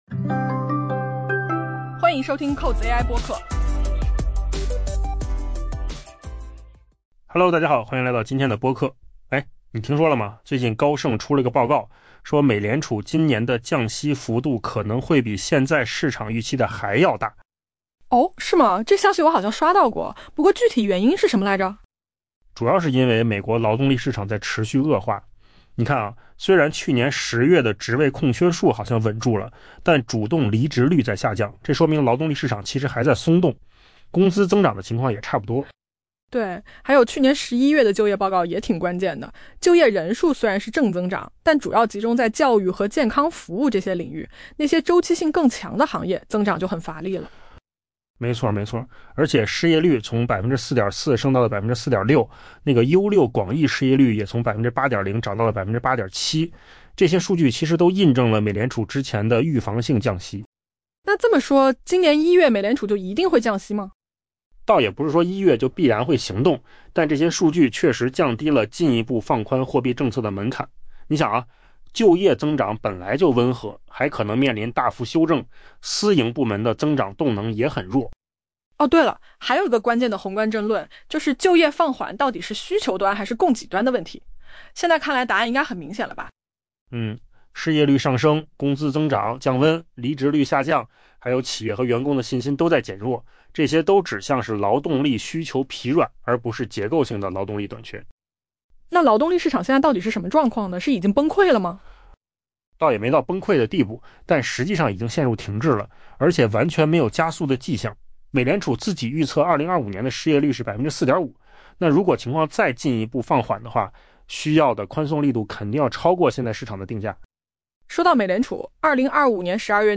AI 播客：换个方式听新闻 下载 mp3 音频由扣子空间生成 高盛报告指出，美联储今年降息幅度很可能超过央行官员与金融市场目前的预期。